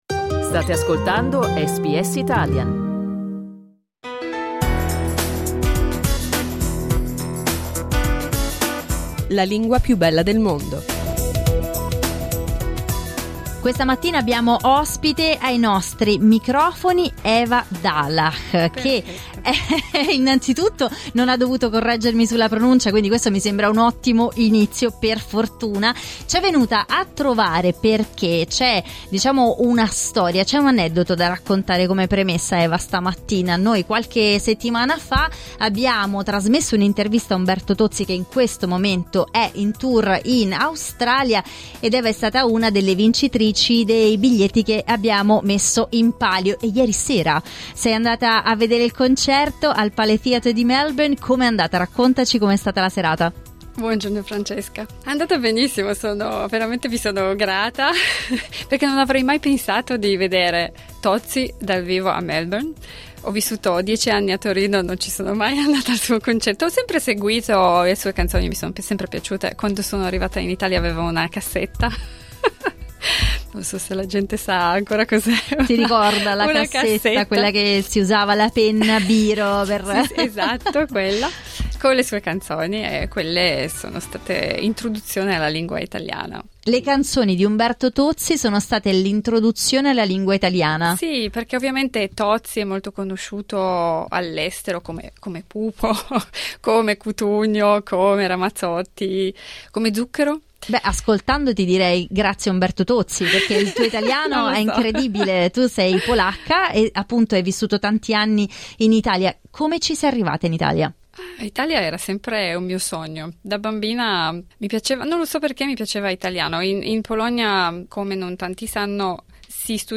negli studi di SBS a Melbourne.